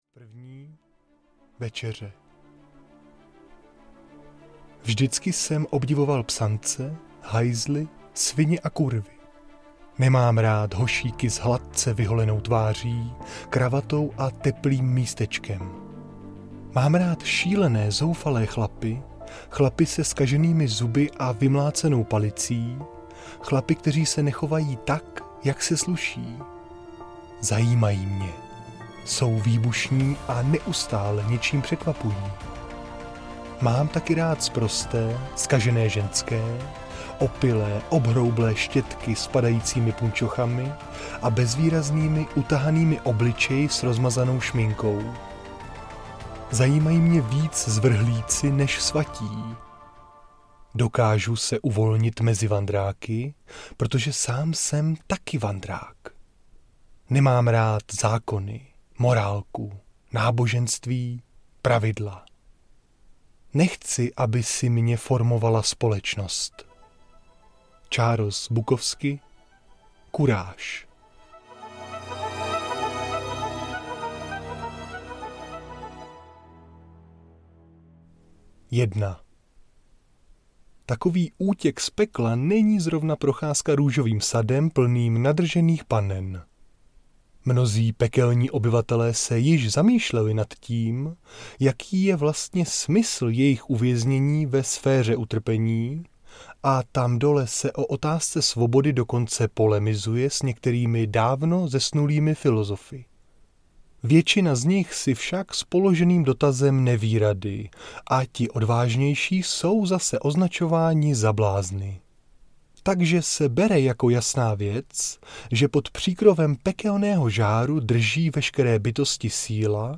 Čepel entropie audiokniha
Ukázka z knihy